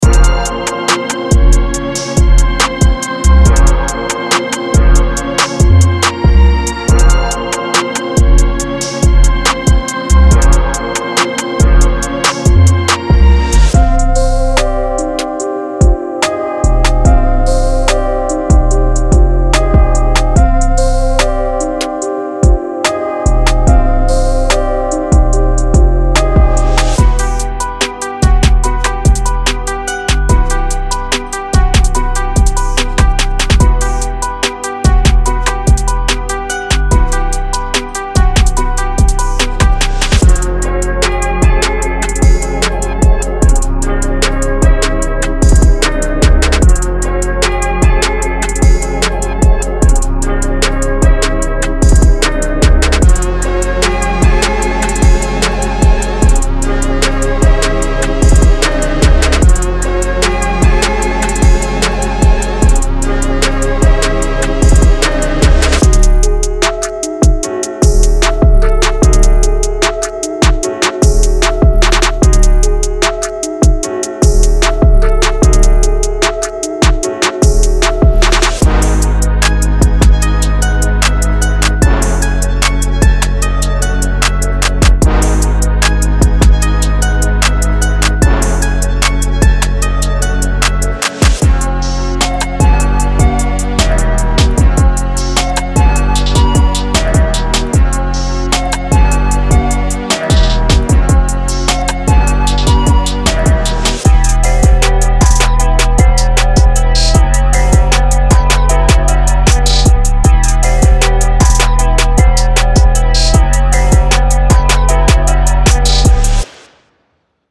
Hip-Hop / R&B Trap
• 16 Bass Loops
• 47 Melody Loops